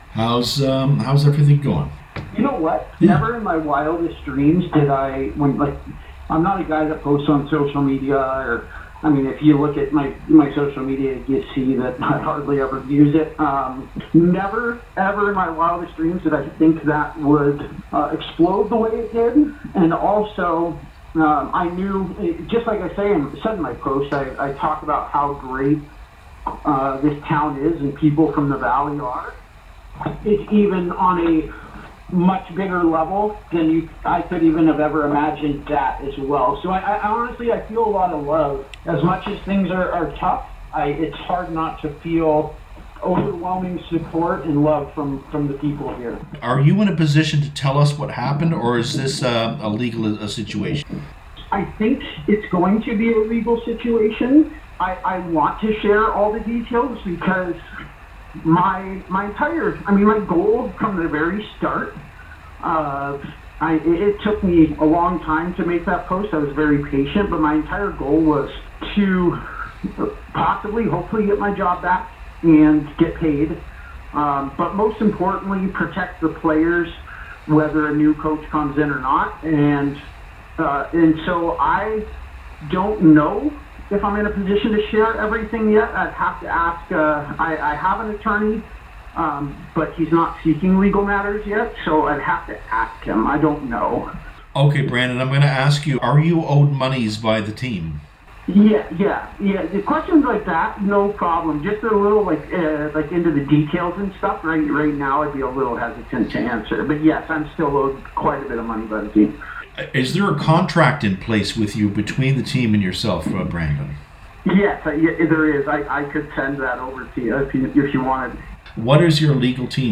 In an exclusive interview with myFM